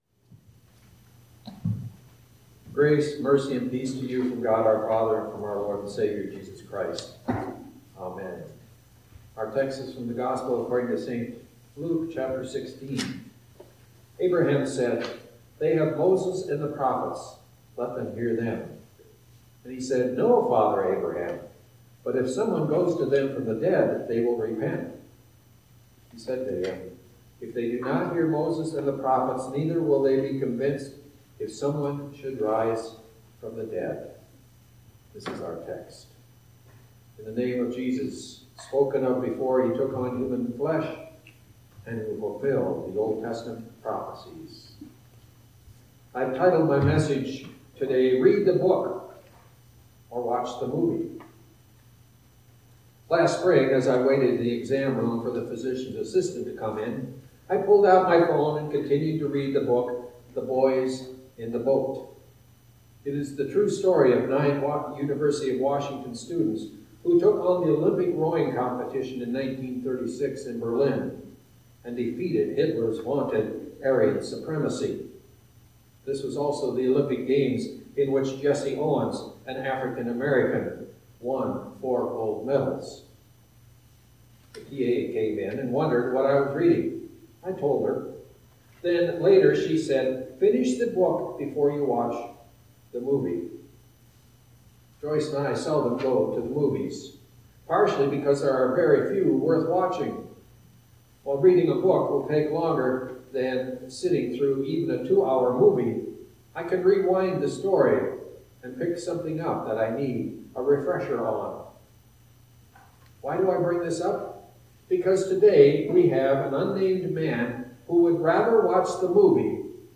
A sermon from the season "Trinity 2025."